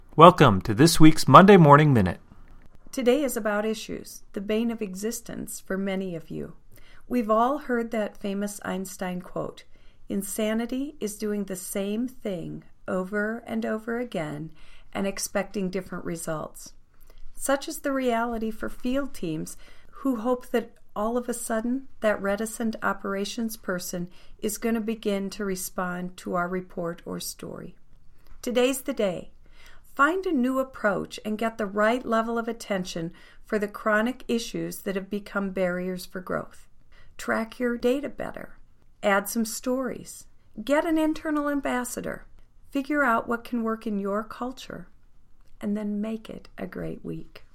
Welcome to B/Mc’s Monday Morning Minute, an audio message to jump start your week.